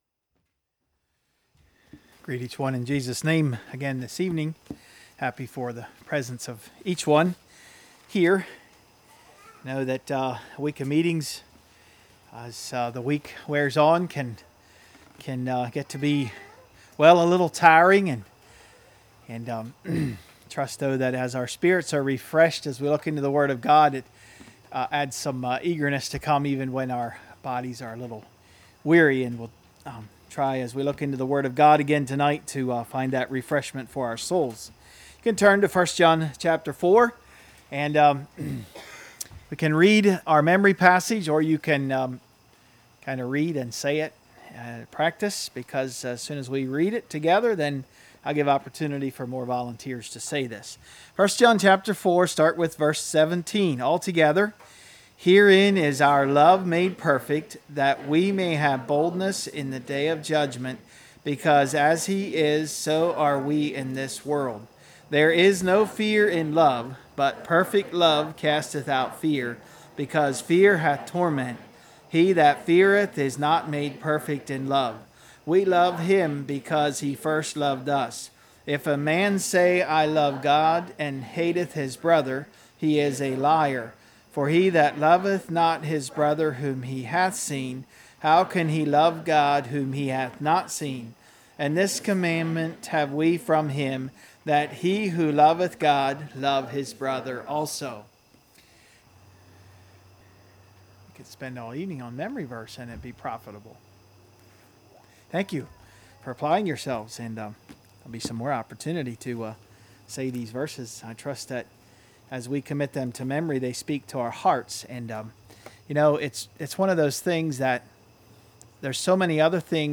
2016 Sermon ID